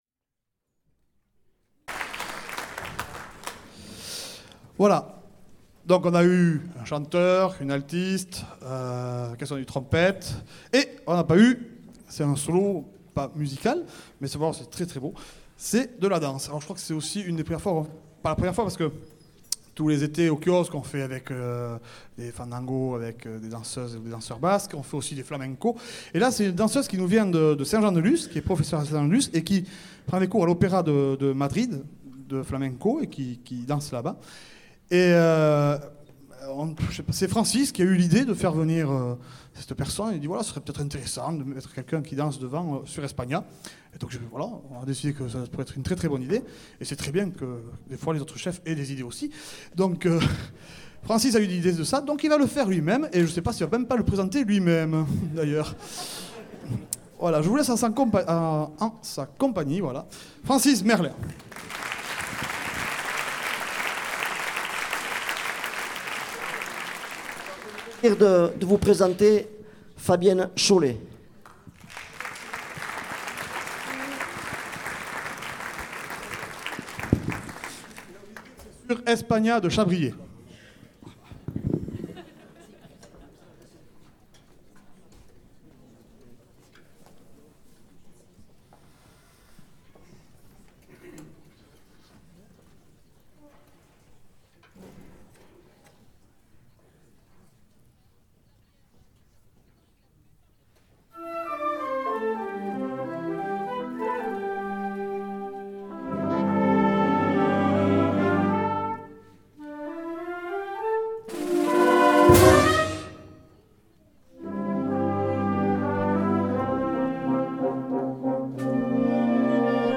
Ecoutez des extraits de concerts de l'Harmonie Bayonnaise
Quelques concerts de l’Harmonie Bayonnaise en écoute:
Théâtre Municipal de Bayonne, le 10 avril 2011 : 1ère partie 2ème partie